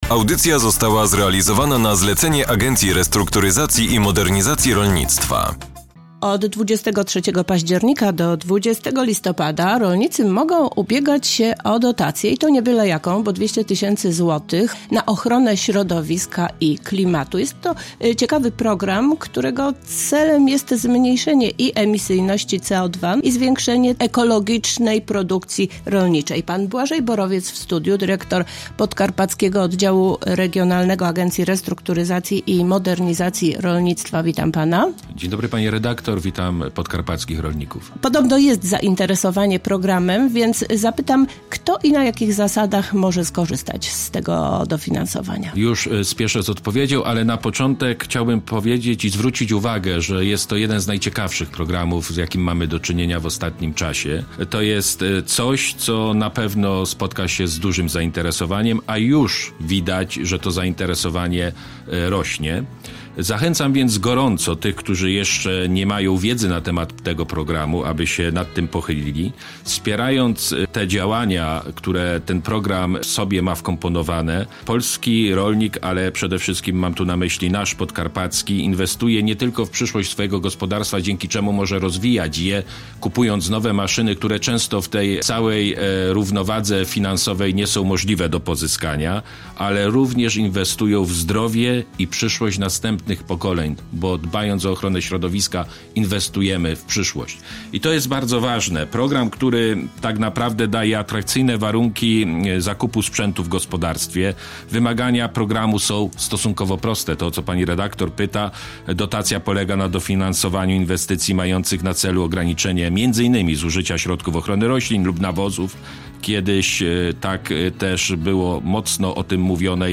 Do 20 września przedłużony został termin składania wniosków o dofinansowanie małych gospodarstw. To program, którego celem jest skrócenie drogi od konsumenta do producenta. W rozmowie przedstawiono idee projektu i zasady skorzystania z pomocy ARiMR.